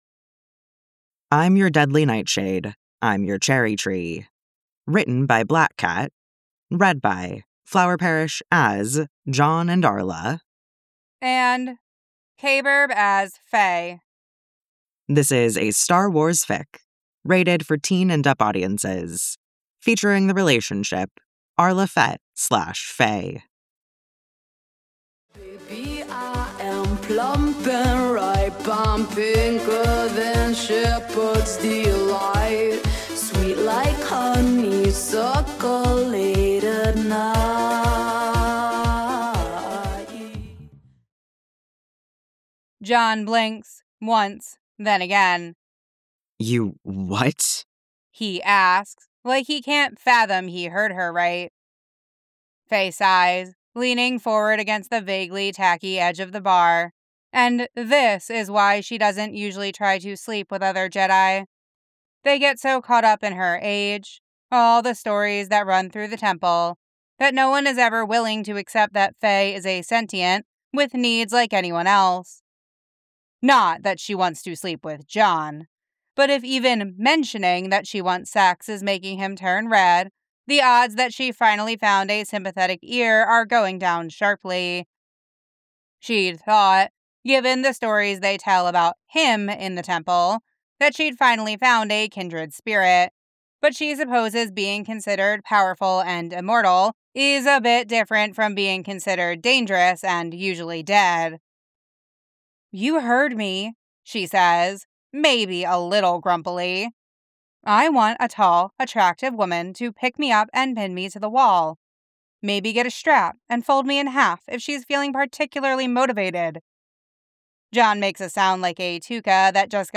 collaboration|ensemble